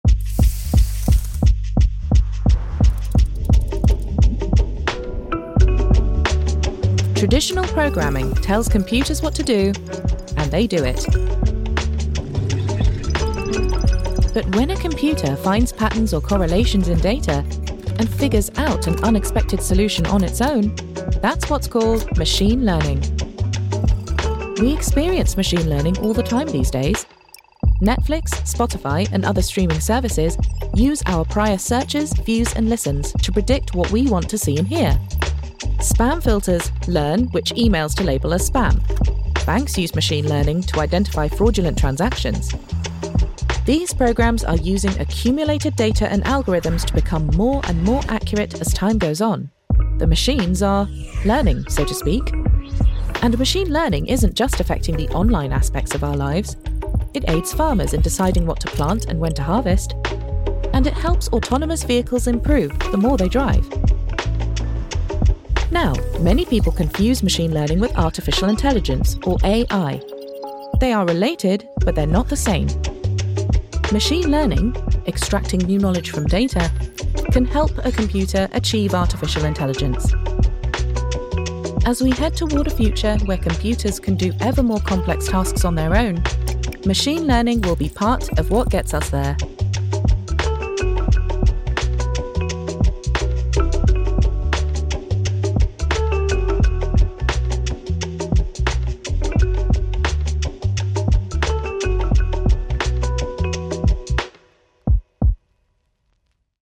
Anglais (Britannique)
Polyvalente, Corporative, Fiable
Vidéo explicative